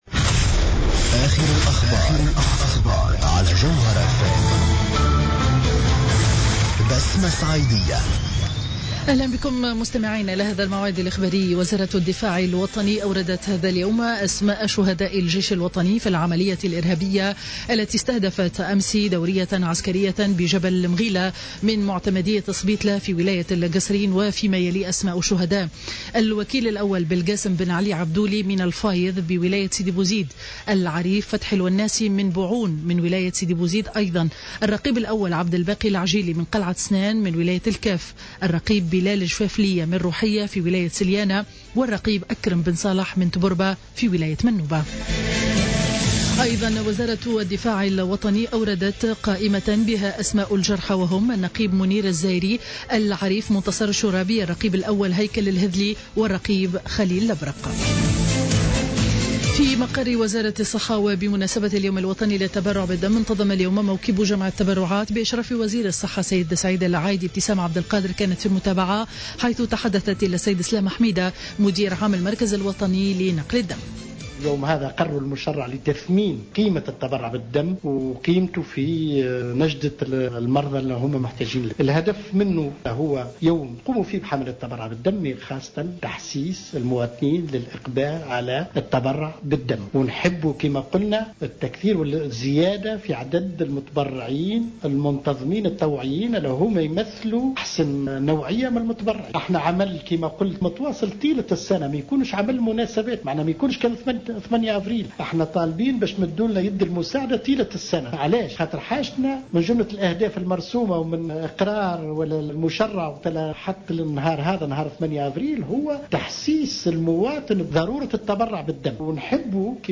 نشرة أخبار منتصف النهار ليوم الإربعاء 8 أفريل 2015